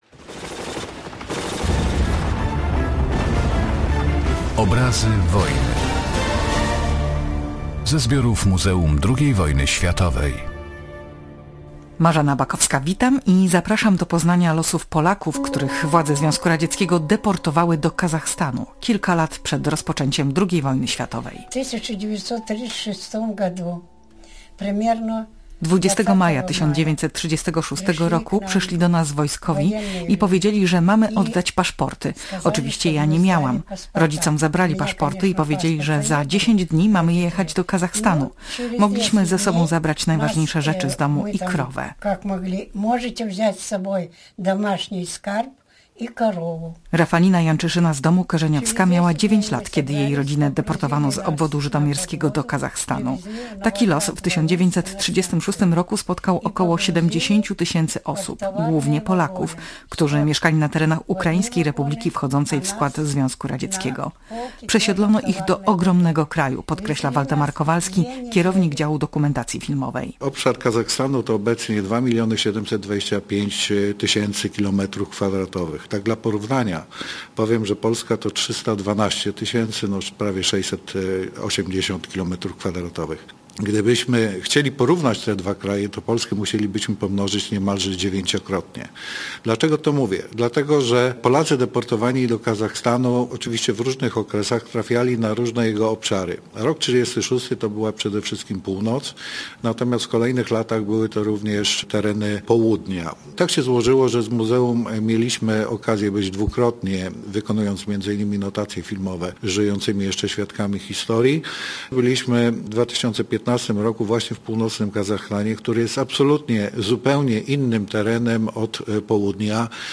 Przytaczamy relacje świadków, którzy, jako dzieci, zostali deportowani.